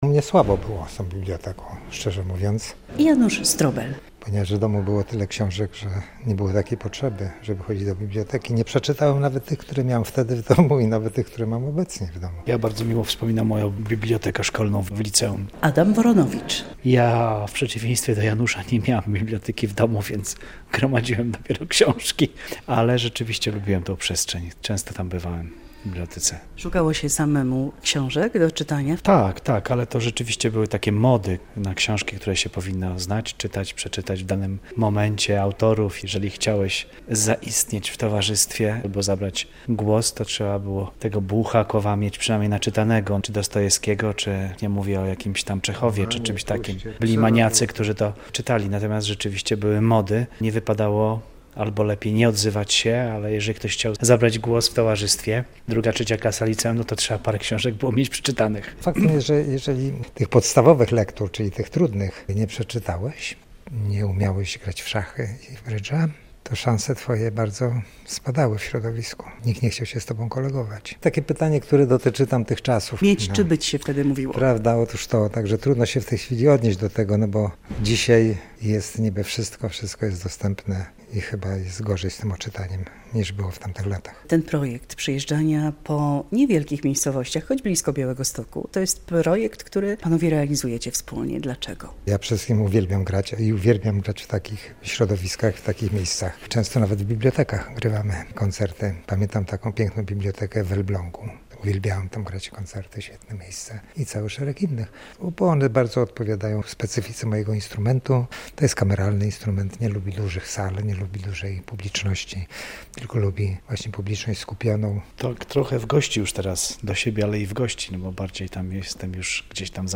Noc Bibliotek w Choroszczy